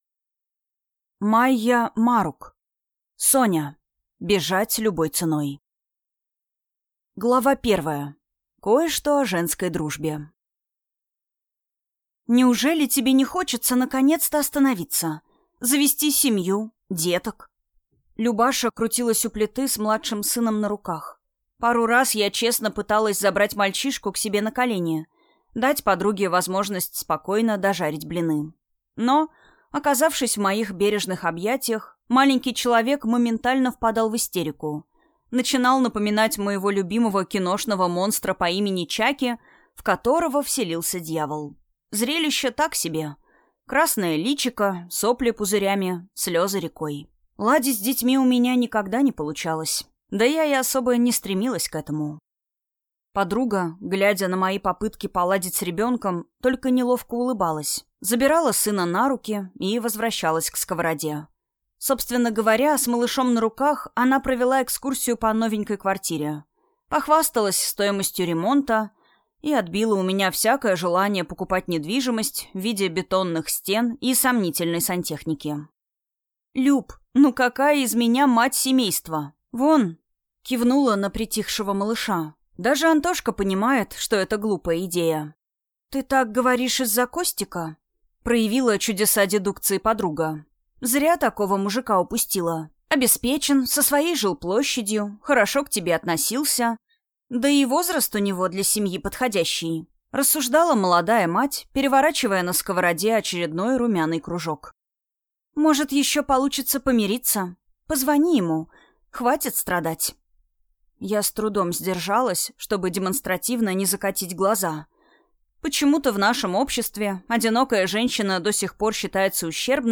Аудиокнига Соня. Бежать любой ценой | Библиотека аудиокниг